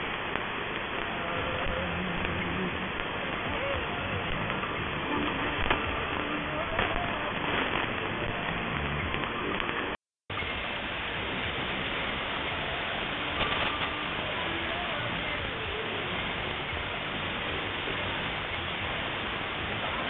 The noiseblanker of the Cloud-IQ badly filters out the local interferences and sometimes not at all. There is a high level of background noise in the Perseus.
Reception in AMS and 7 KHz bandwidth.